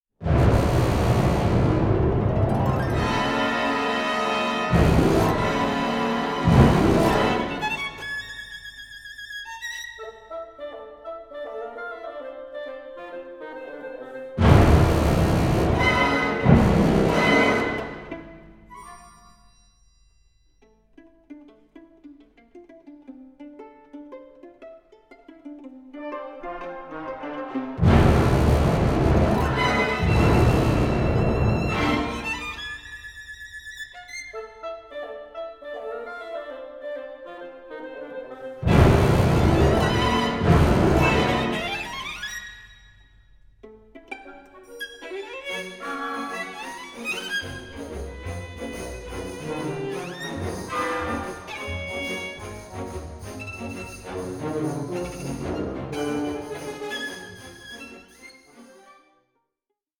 Cheeky and lively 6:29